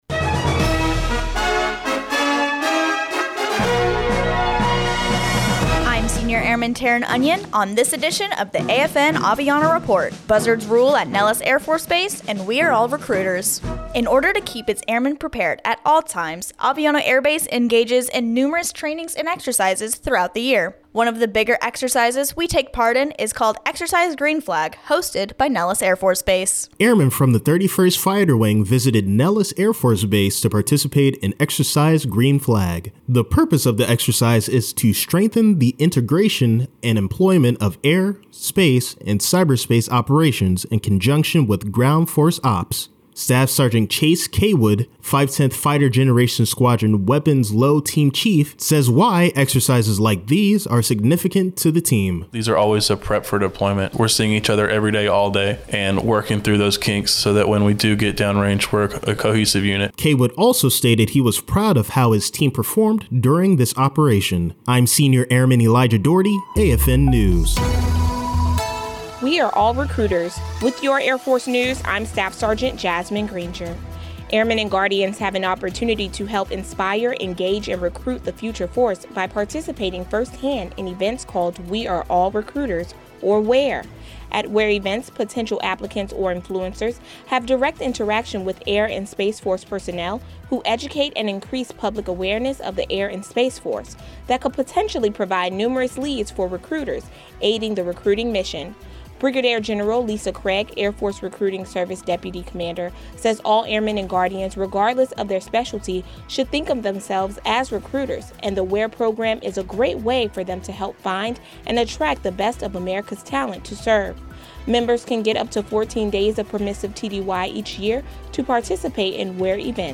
American Forces Network (AFN) Aviano radio news reports on the 510th Fighter Generation Squadron's participation in Exercise Green Flag - 2023